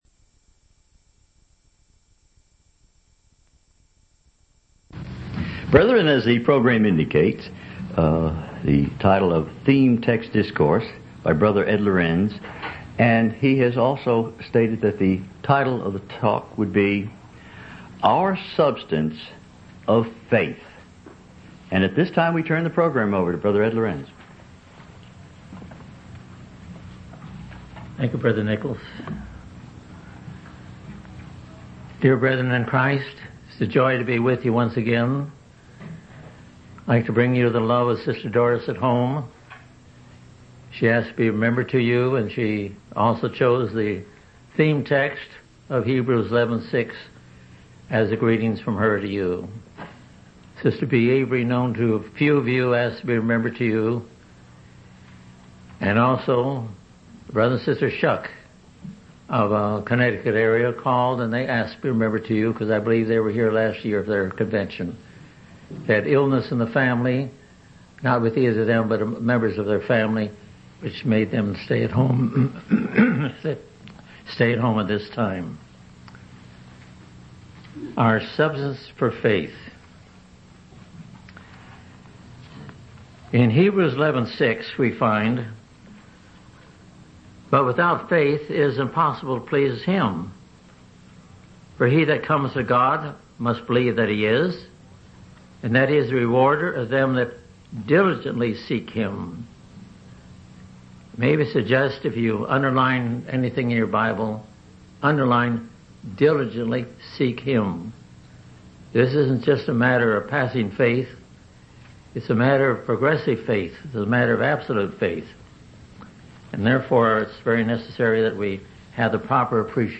From Type: "Discourse"
Given at San Antonio, Convention 4/26/1997